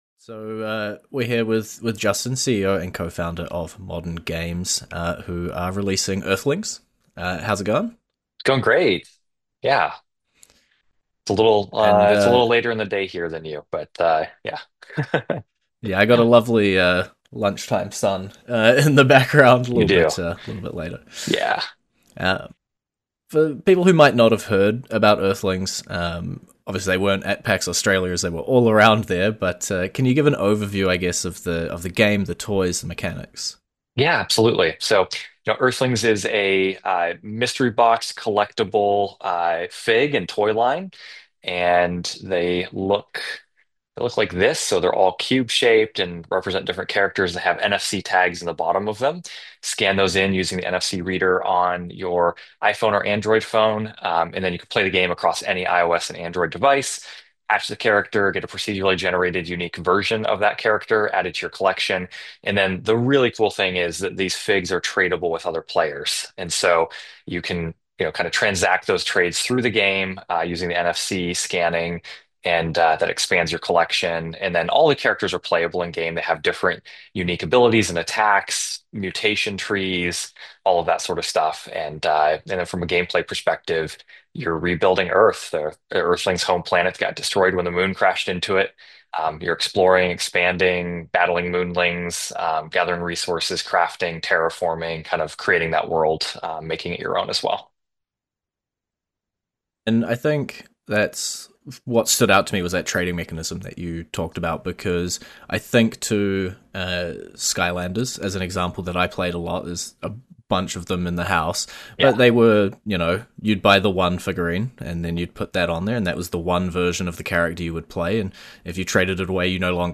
Featuring interviews with staff from game development studios!